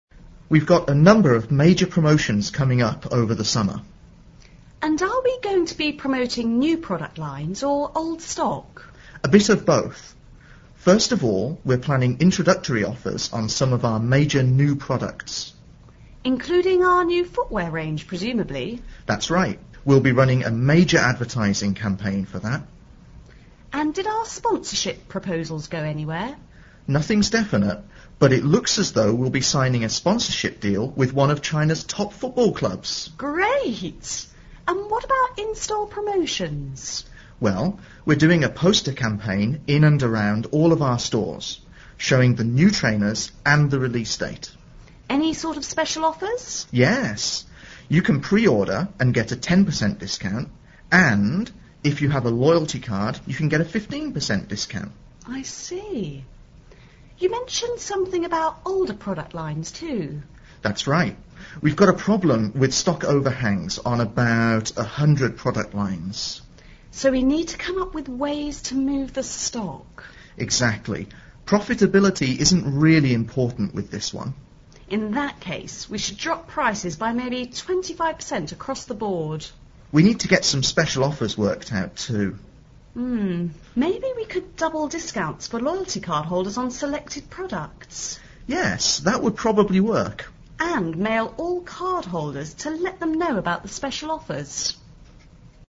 Listen to the dialogue between the Head of Marketing (HM) and the Marketing Manager (MM)